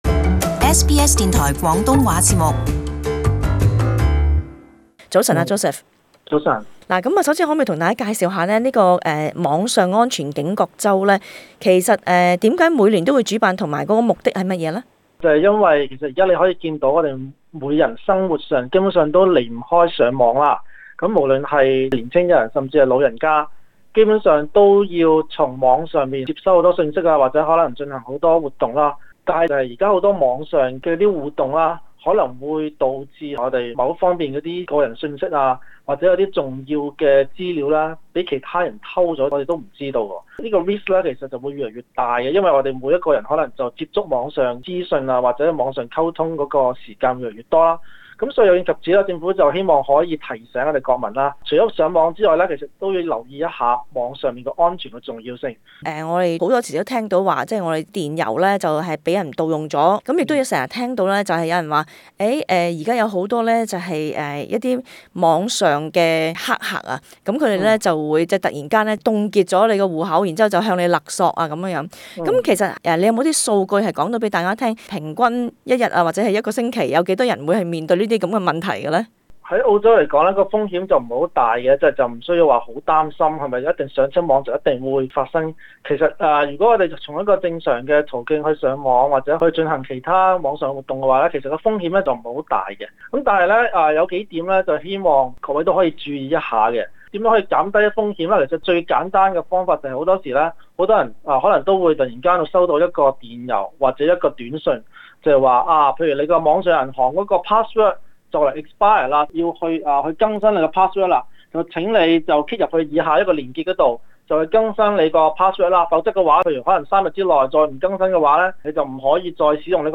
【社區專訪】如何保障你的網上安全？